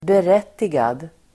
Uttal: [ber'et:igad]